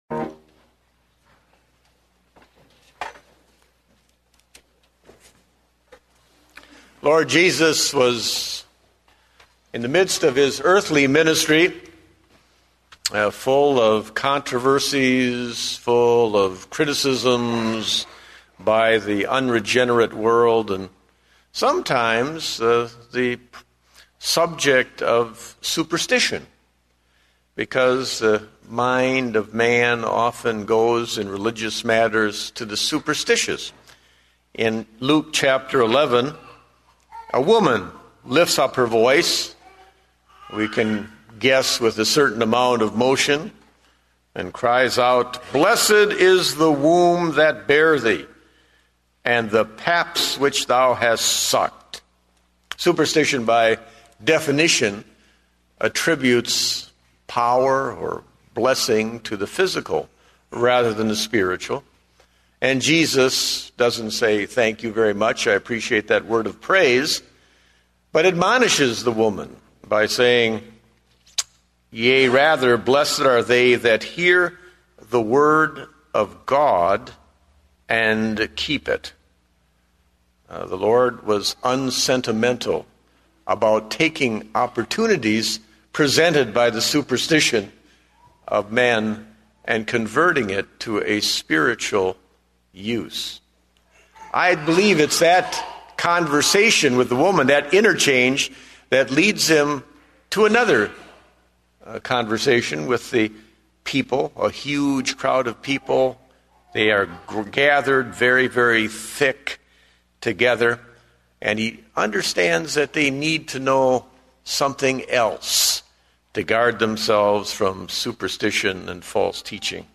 Date: April 12, 2009 (Evening Service)